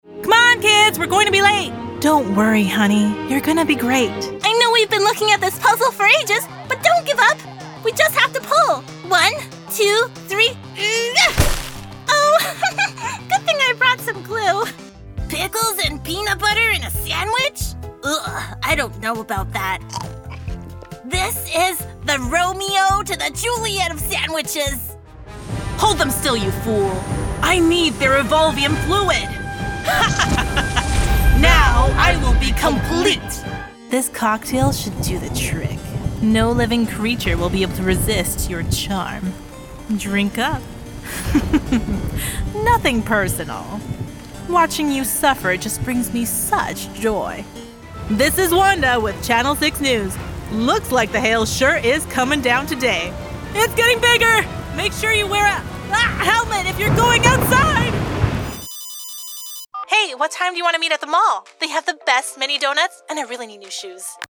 Engels (Amerikaans)
Natuurlijk, Vertrouwd, Vriendelijk